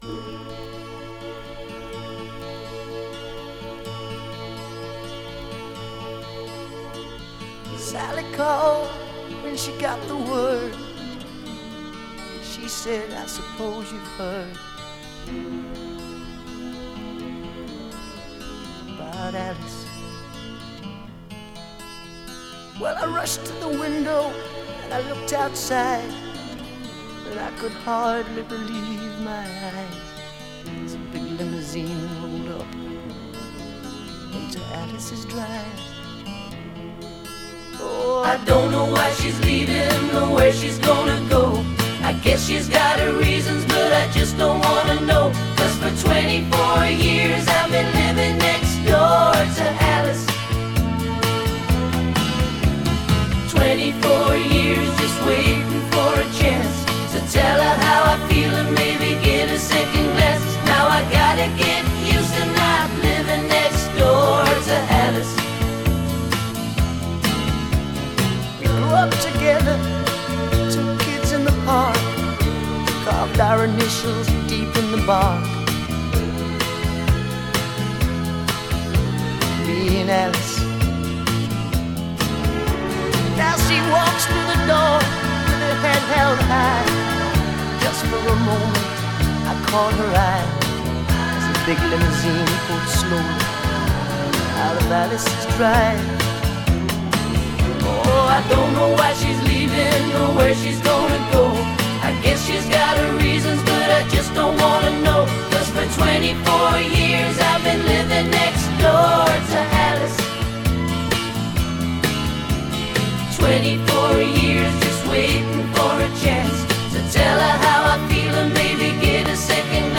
Genre: Pop Rock.